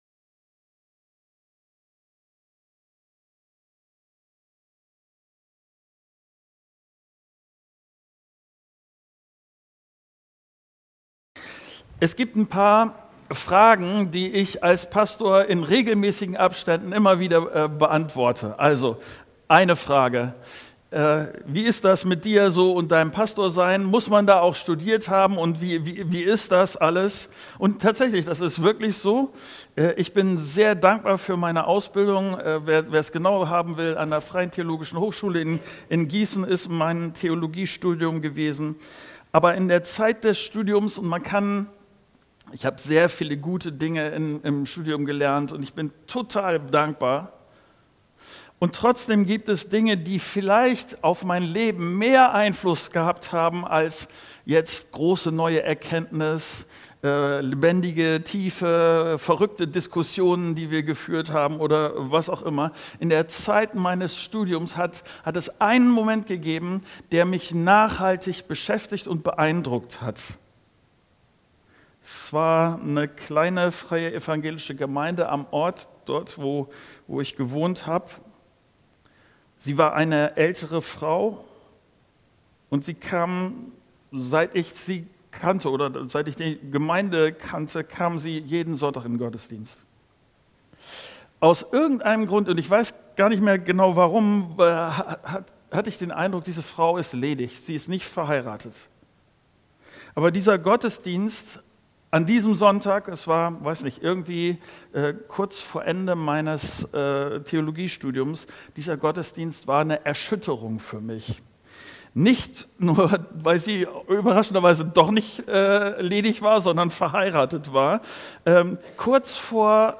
23.11.2025 - Klarheit oder Kompromiss ~ Predigten der Christus-Gemeinde | Audio-Podcast Podcast